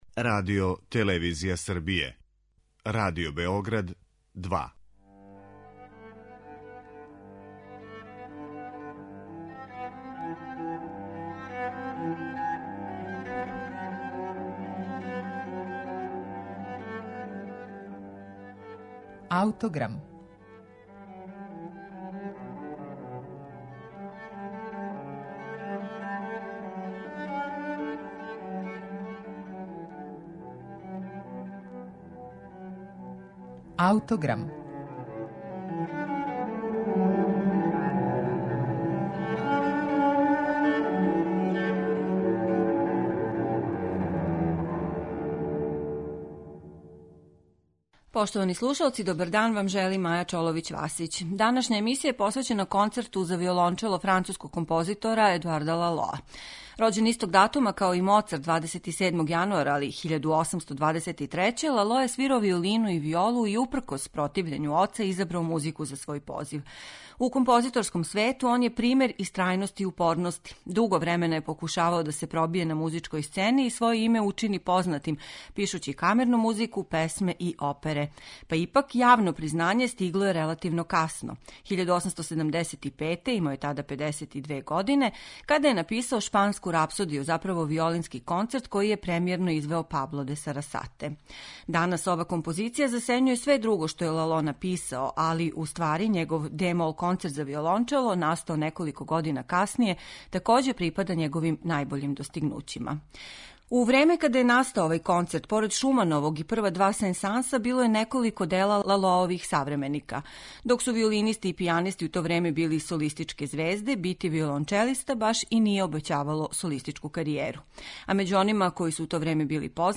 Данашња емисија је посвећена Концерту за виолончело у де-молу Едуарда Лалоа.
Иако у њему нема експлицитног коришћења шпанског фолклора, ритмичке и мелодијске карактеристике музичког материјала сугеришу дух ове земље.